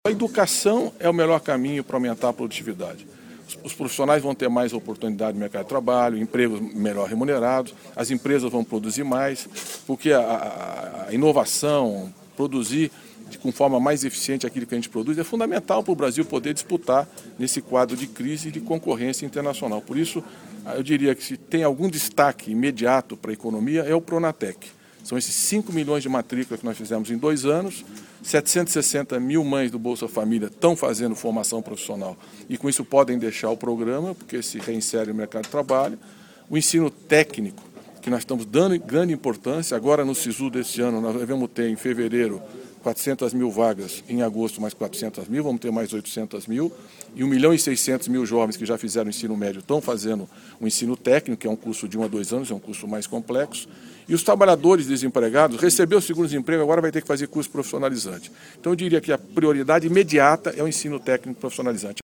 Declaração foi dada pelo ministro durante 4º Fórum Estadão Brasil Competitivo, nesta terça-feira (19) em São Paulo
Ouça o que ele disse ao Portal da Indústria clicando